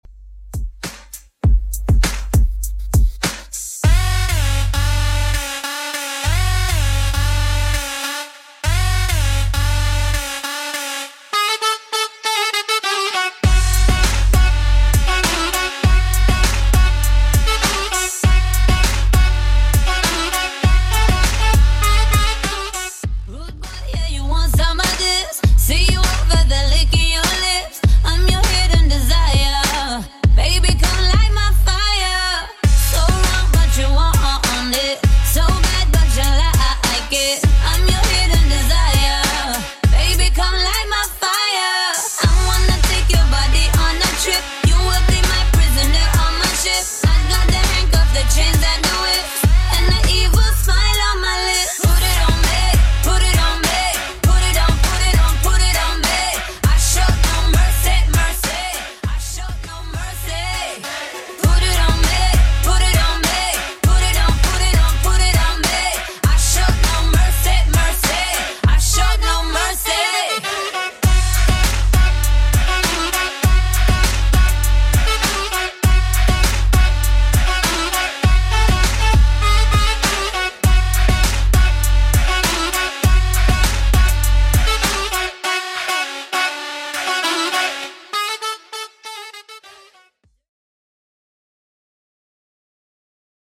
Genre: 80's
BPM: 115